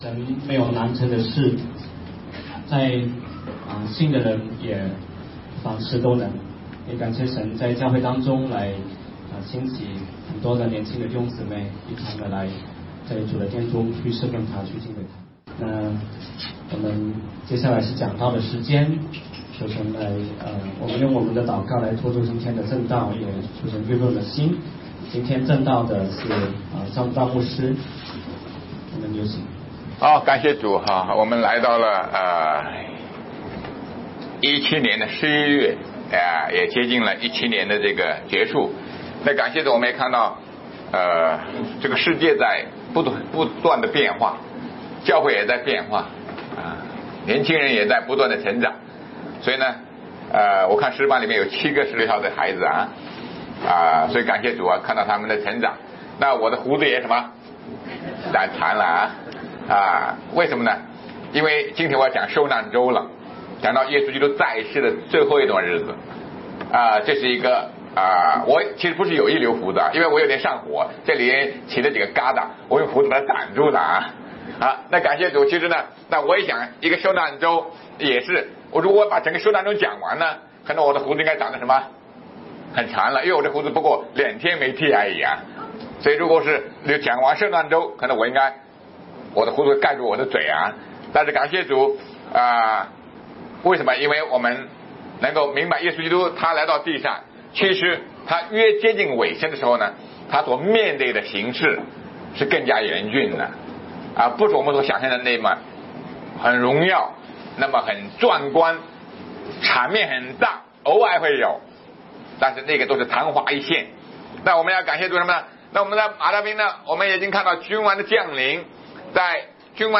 马太福音第32讲 对君王的排斥I 2017年11月5日 下午7:51 作者：admin 分类： 马太福音圣经讲道 阅读(5.24K